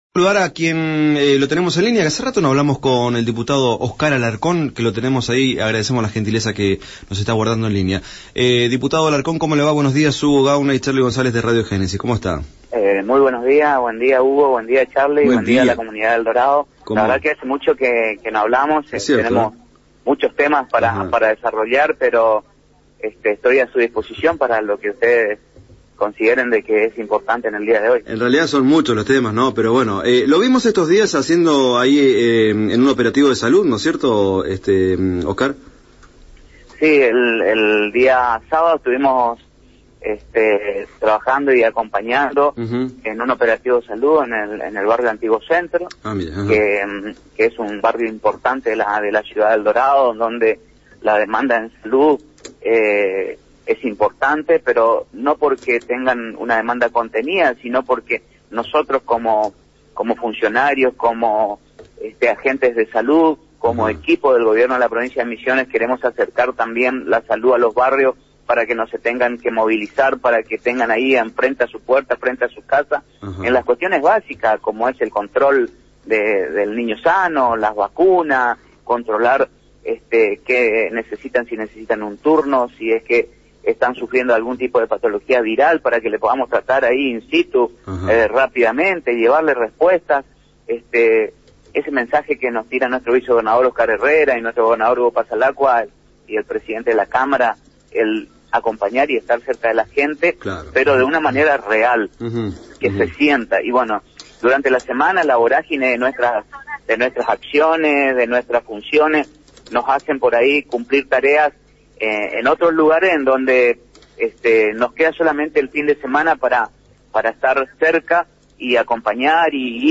Lo confirmó el diputado provincia Oscar Alarcón en comunicación telefónica con ANG y Radio Génesis.